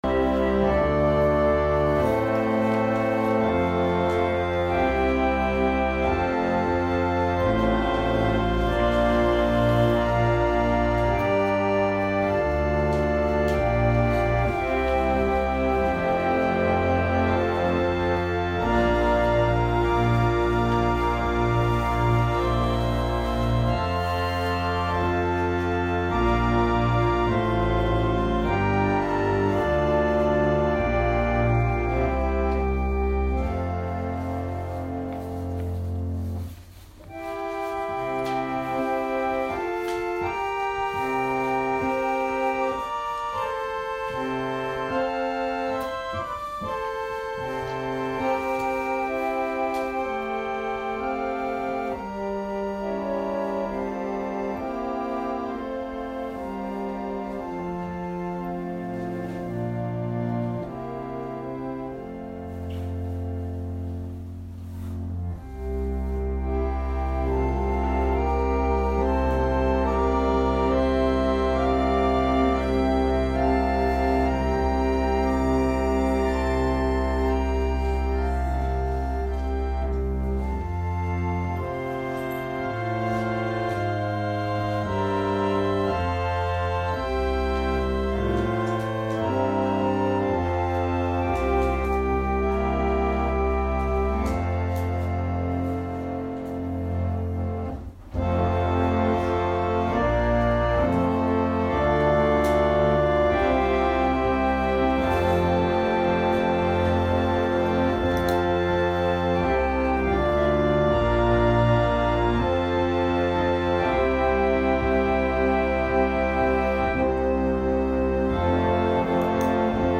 説教アーカイブ。
私たちは毎週日曜日10時20分から12時まで神様に祈りと感謝をささげる礼拝を開いています。
音声ファイル 礼拝説教を録音した音声ファイルを公開しています。